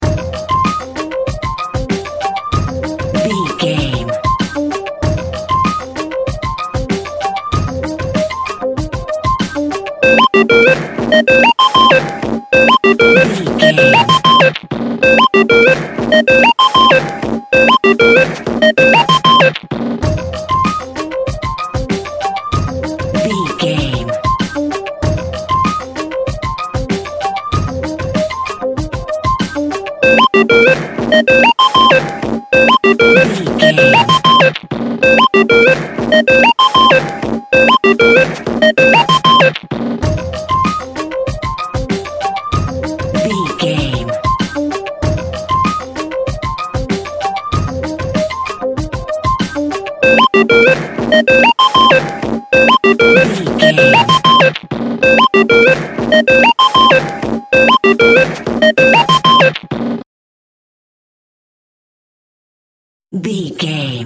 Aeolian/Minor
driving
uplifting
futuristic
hypnotic
industrial
drum machine
techno
trance
glitch
electronic music
synth lead
synth bass